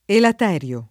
vai all'elenco alfabetico delle voci ingrandisci il carattere 100% rimpicciolisci il carattere stampa invia tramite posta elettronica codividi su Facebook elaterio [ elat $ r L o ] s. m. (bot. «cocomero asinino»); pl. ‑ri (raro, alla lat., ‑rii )